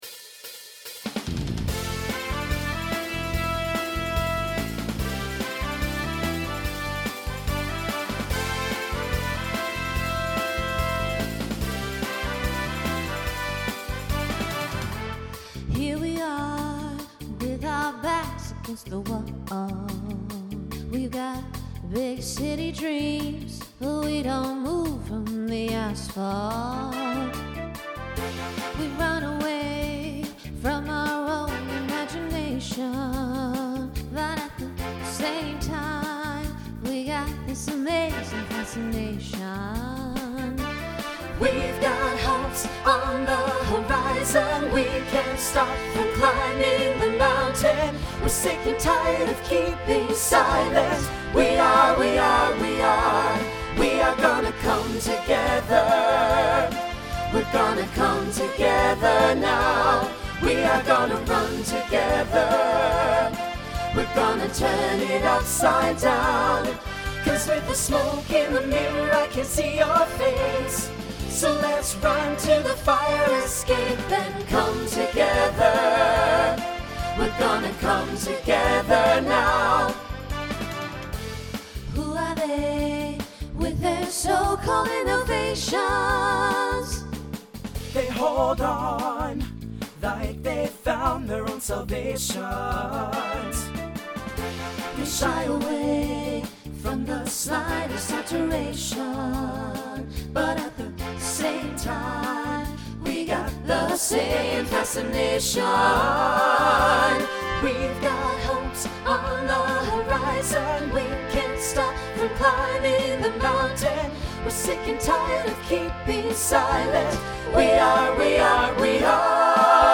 Voicing SATB Instrumental combo Genre Pop/Dance
Mid-tempo